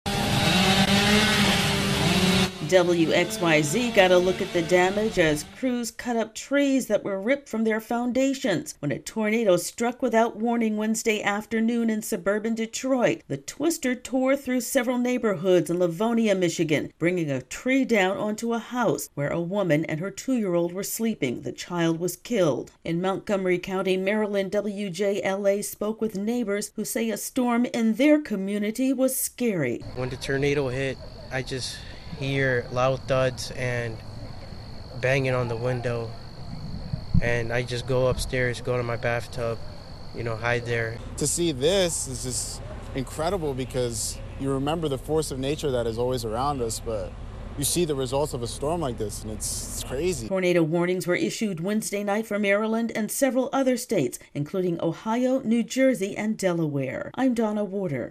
((Begins with chainsaw sound))